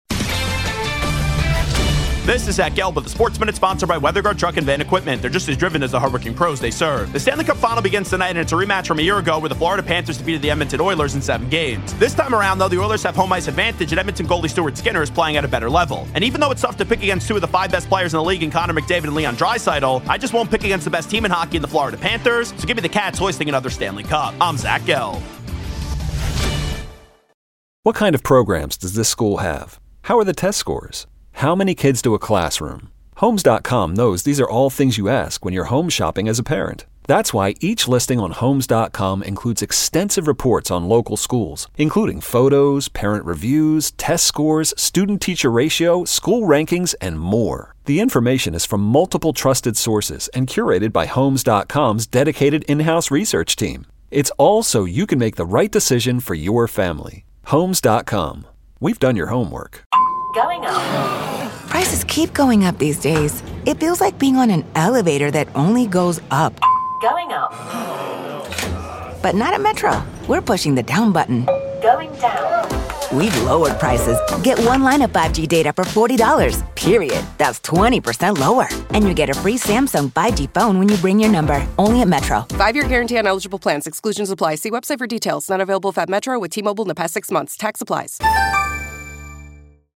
Our show today was live from AVIVA stadium, t…